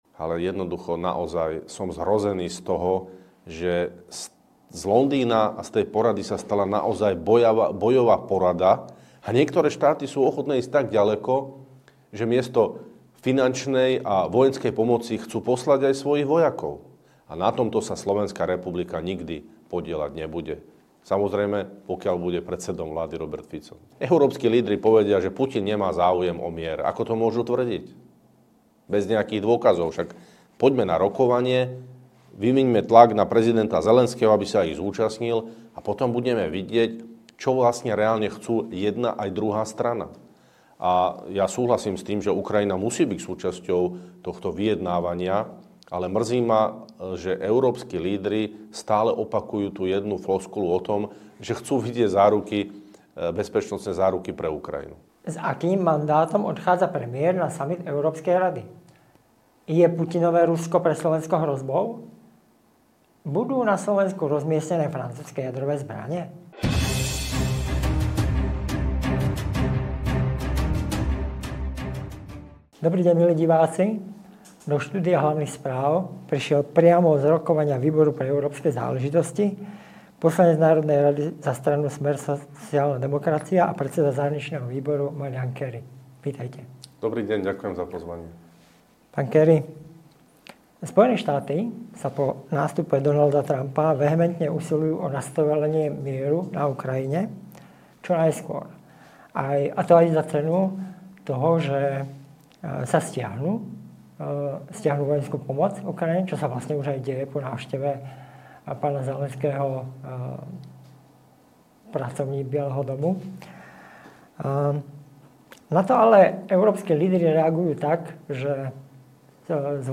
Hneď po skončení výboru do nášho štúdia zavítal Marián Kéry, poslanec NR SR a predseda Zahraničného výboru NR SR, aby našim divákom priblížil ako sa veci v tejto chvíli majú.